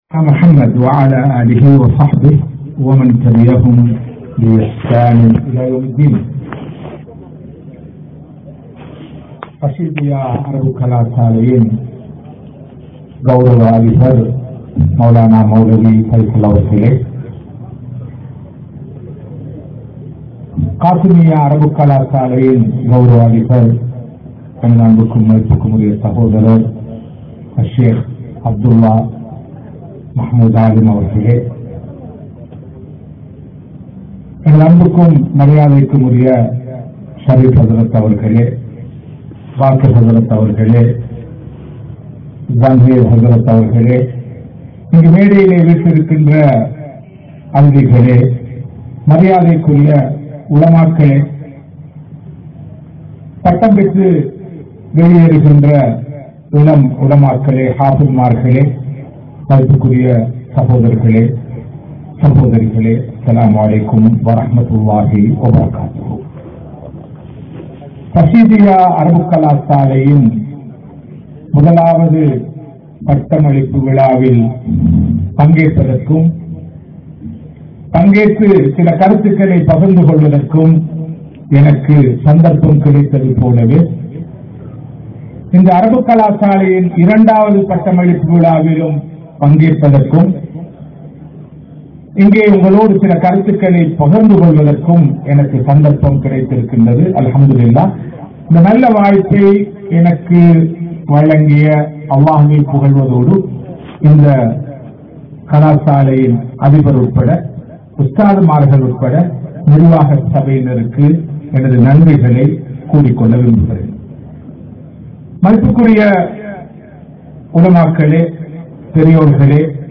Matharsaakalin Indraya Nilamai (மத்ரஸாக்களின் இன்றைய நிலமை) | Audio Bayans | All Ceylon Muslim Youth Community | Addalaichenai
Puttalam, Kadaiyamoattai, Rasheedhiya Arabic College